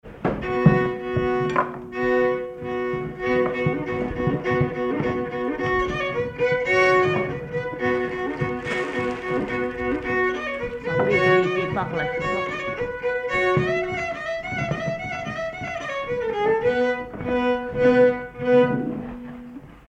Villard-sur-Doron
circonstance : bal, dancerie
Pièce musicale inédite